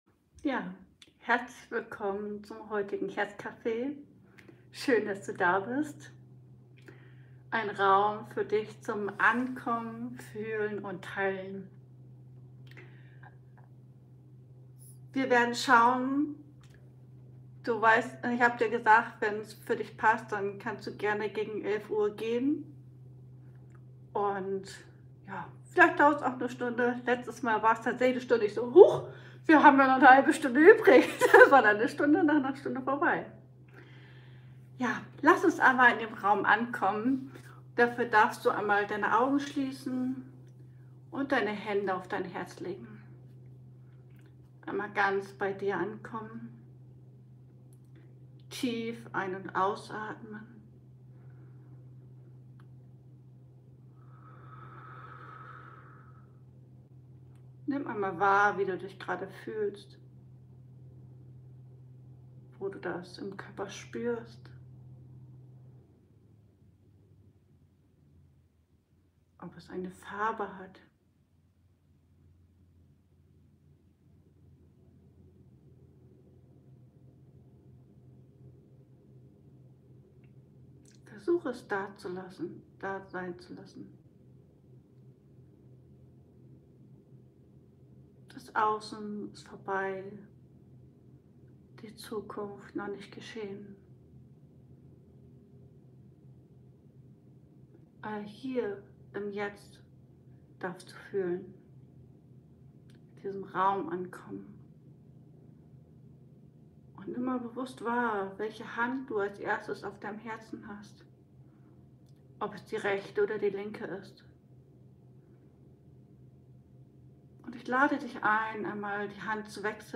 Liveübertragung Herz Café ~ Ankommen ~ Fühlen ~ Teilen ~ Ankommen lassen Podcast